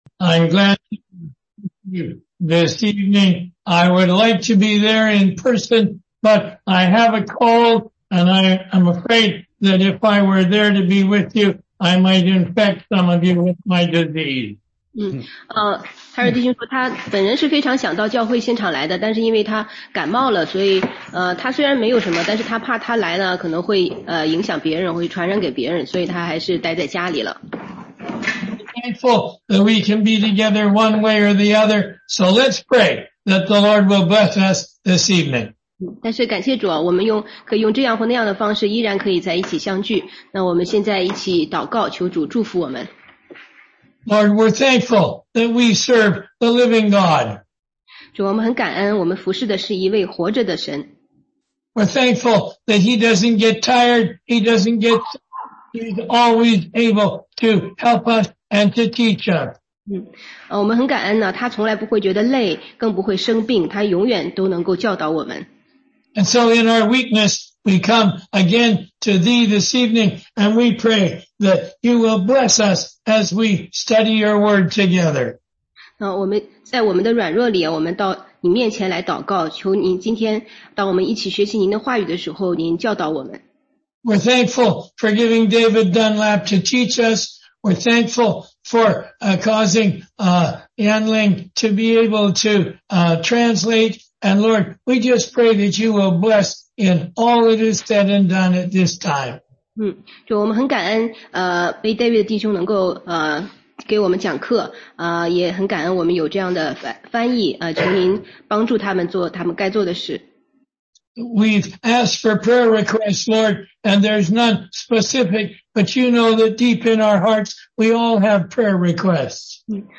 16街讲道录音 - 中英文查经